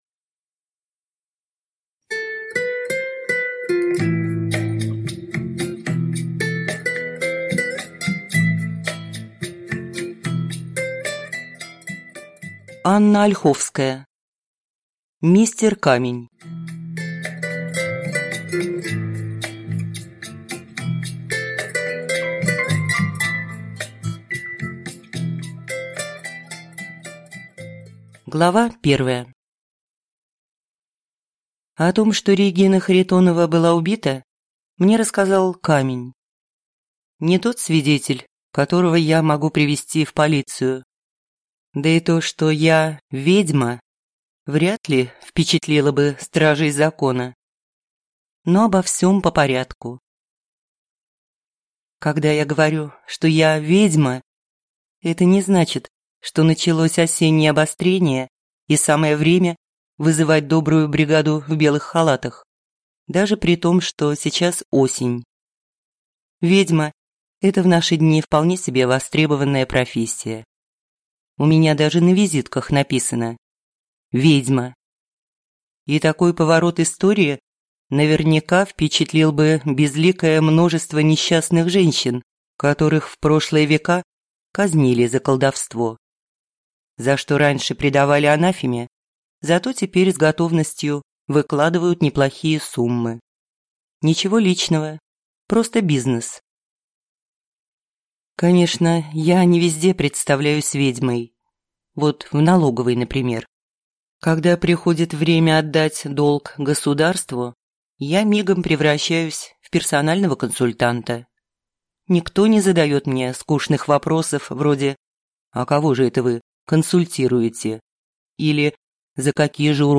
Аудиокнига Мистер Камень | Библиотека аудиокниг
Прослушать и бесплатно скачать фрагмент аудиокниги